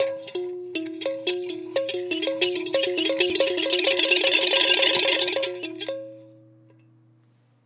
VEC3 FX Athmosphere 22.wav